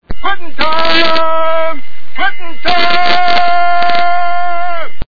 The sound bytes heard on this page have quirks and are low quality.